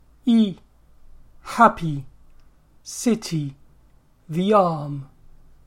i (happy, city, the arm)